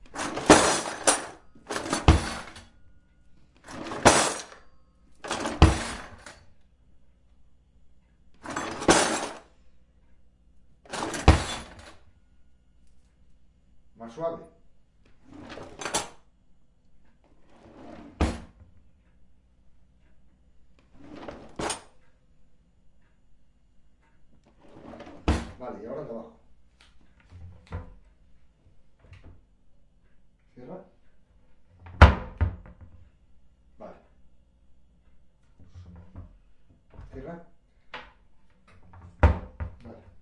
打开和关闭厨房的抽屉
描述：以不同的强度打开和关闭，从一个装满银器的厨房抽屉里。
Tag: 洁具 抽屉 众议院的声音 厨房的声音 弗利